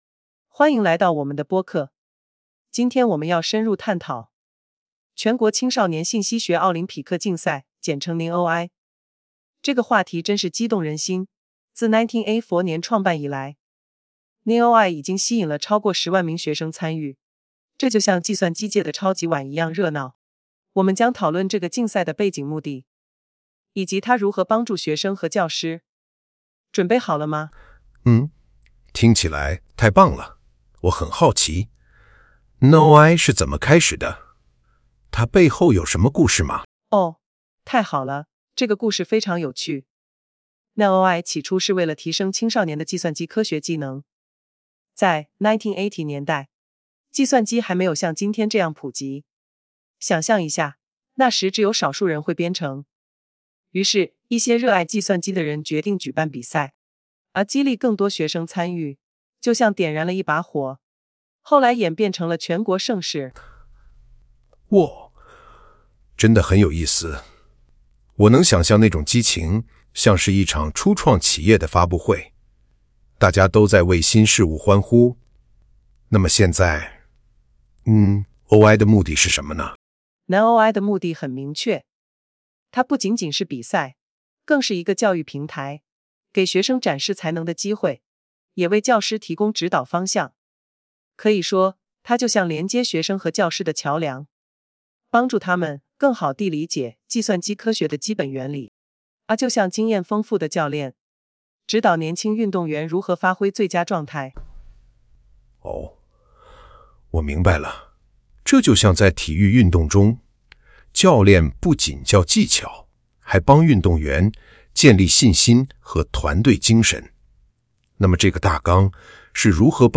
上传PDF文件，自动生成双人播客
系统在没有额外监督的情况下，基于零样本学习快速生成自然、流畅且忠实于原文的语音。
Speaker 1 播者1 就使用女声参考音频生成音频结果；Speaker 2 播者2 就使用男声参考音频生成音频结果。